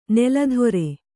♪ nela dhore